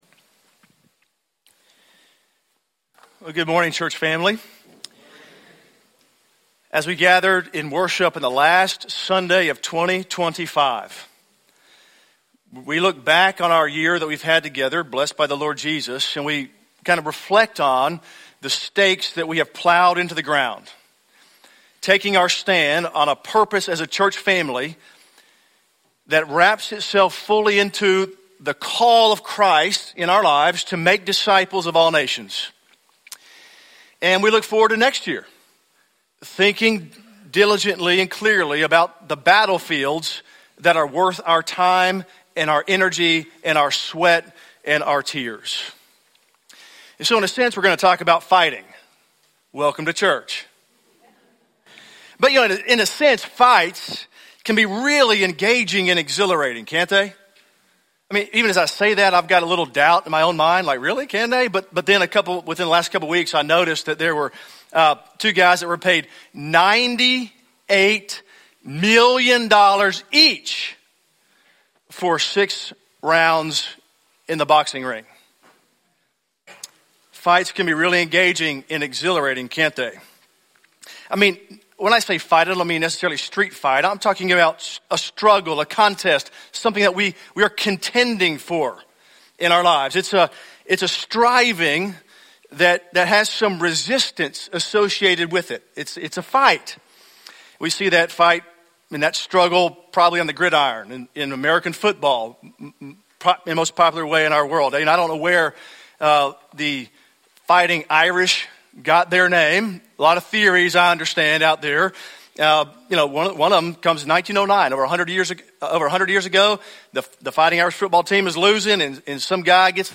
Sermon Archives
From Series: "English Sermons - 10:15"